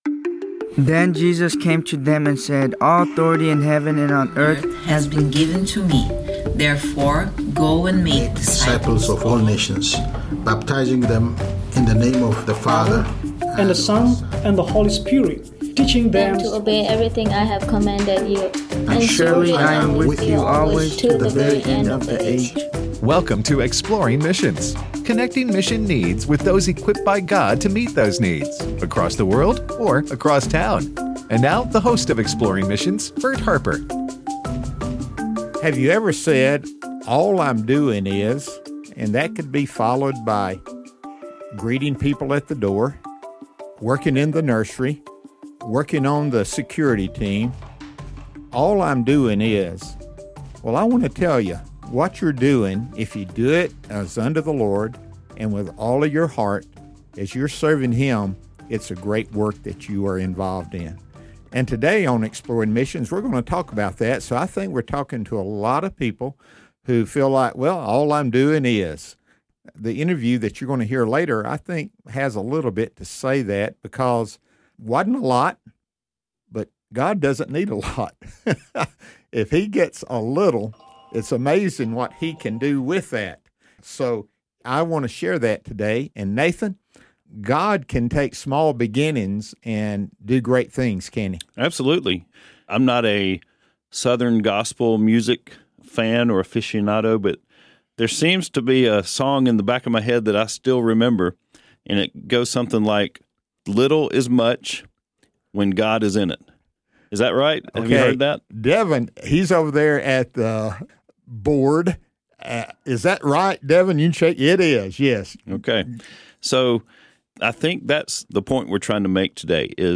Operation Care International: A Conversation